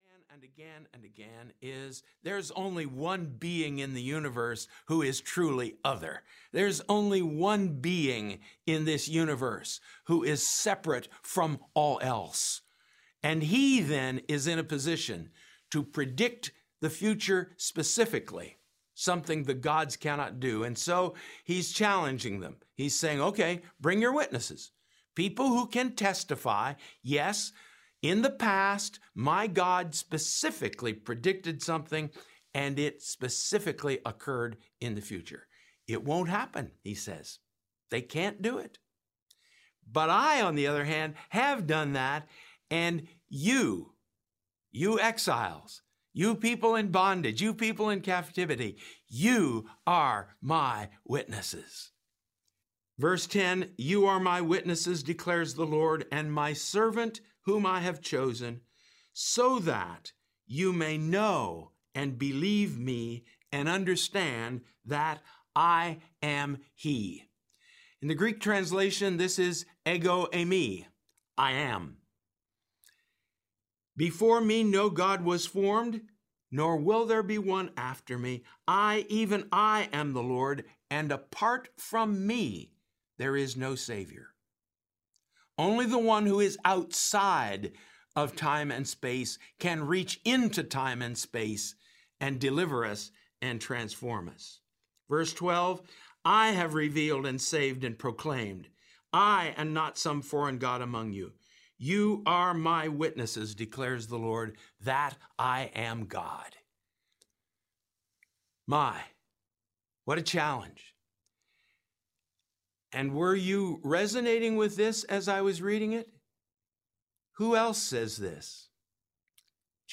Isaiah 40-66: Audio Lectures Audiobook